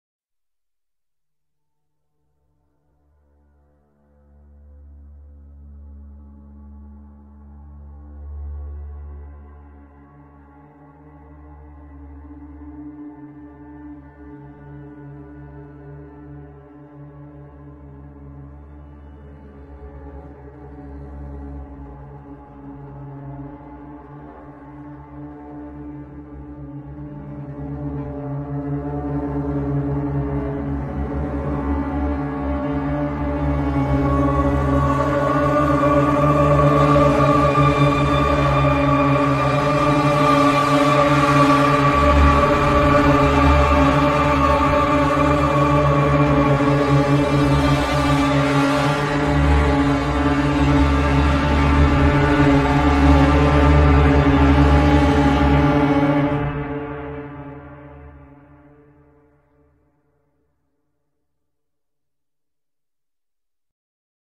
Sound Effects Soundboard779 views